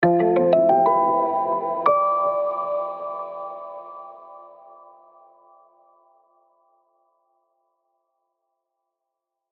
10秒BGM （103件）